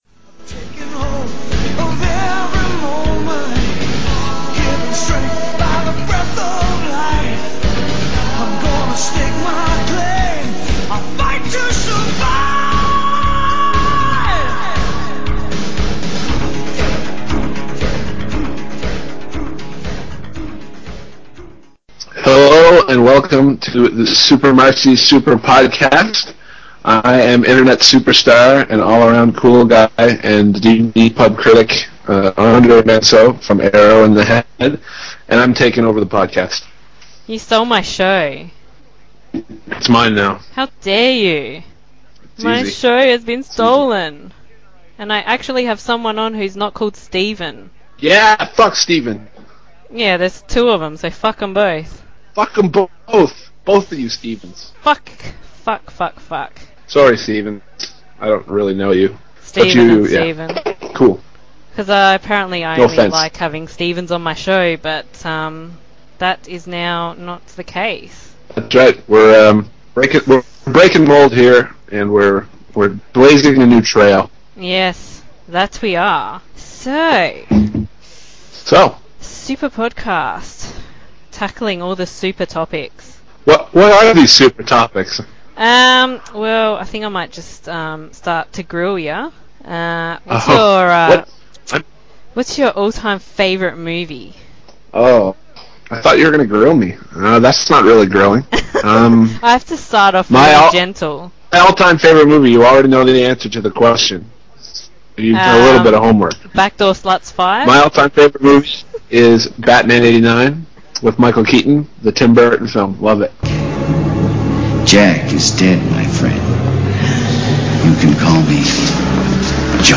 This podcast has a lot of swearing in it, you have been warned!
You will have to excuse the quality a little bit as my internet was epic fail when we recorded.